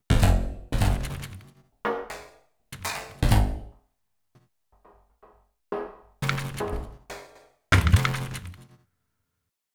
Synth_11.wav